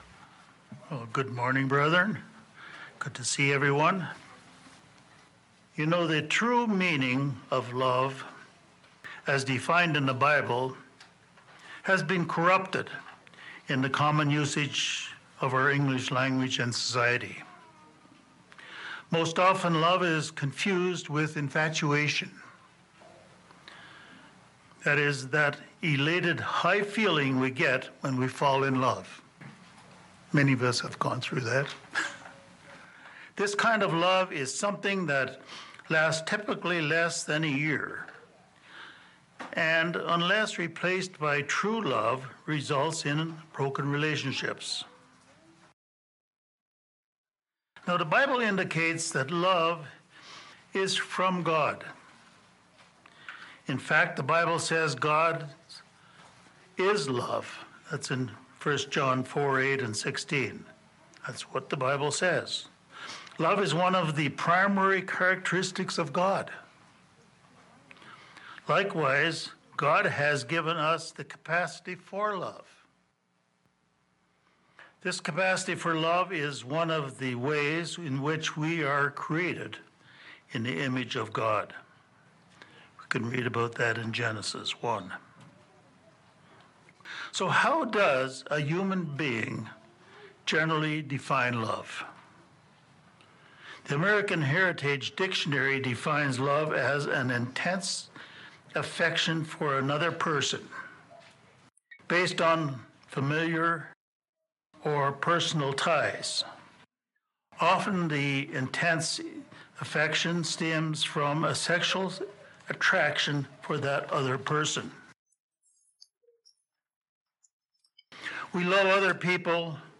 This sermon discusses the origin of Godly love as defined in the scriptures. It first compares human type of love (phileo) to God’s love as described as agape.
Given in Denver, CO